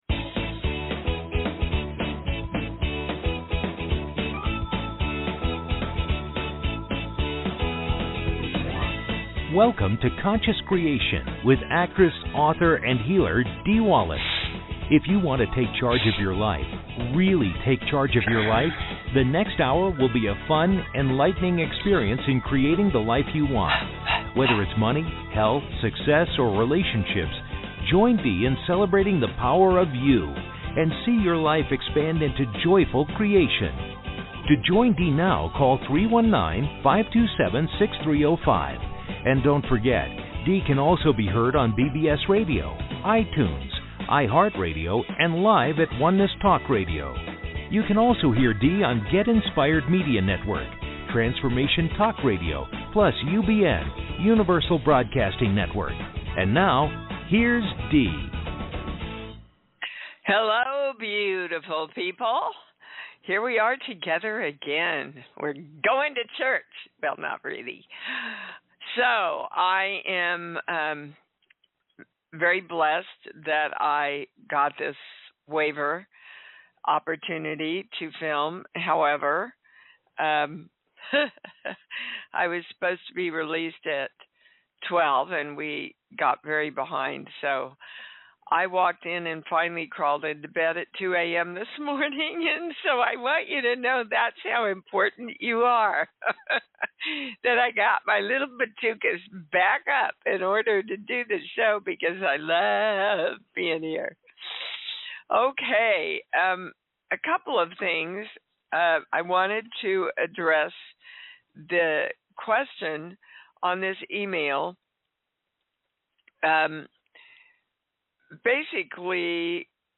Talk Show Episode, Audio Podcast, Conscious Creation and with Dee Wallace on , show guests , about Dee Wallace,conscious creation,I am Dee Wallace, categorized as Kids & Family,Philosophy,Psychology,Self Help,Society and Culture,Spiritual,Access Consciousness,Medium & Channeling,Psychic & Intuitive